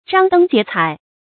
张灯结彩 zhāng dēng jié cǎi 成语解释 挂上灯笼；系上彩绸。
成语繁体 張燈結彩 成语简拼 zdjc 成语注音 ㄓㄤ ㄉㄥ ㄐㄧㄝ ˊ ㄘㄞˇ 常用程度 常用成语 感情色彩 中性成语 成语用法 联合式；作谓语、状语；含褒义 成语结构 联合式成语 产生年代 古代成语 成语正音 结，不能读作“jiē”。